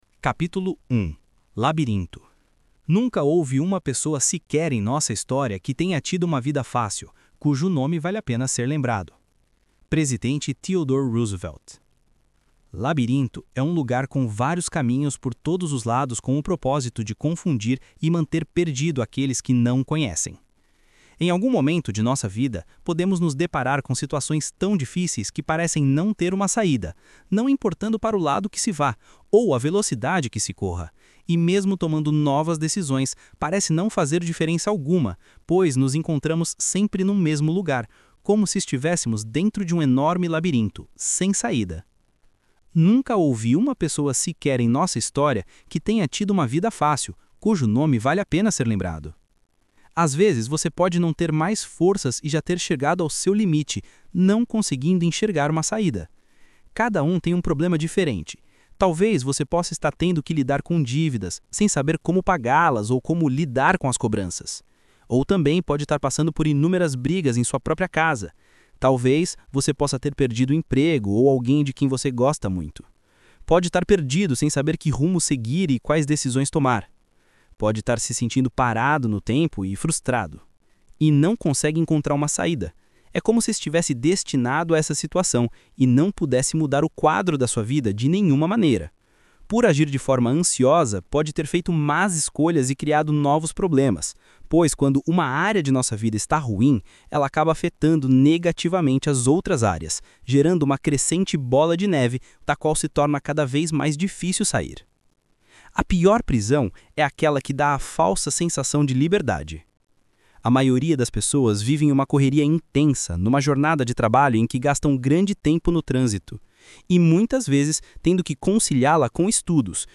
O áudiobook do primeiro capítulo baixará em breve.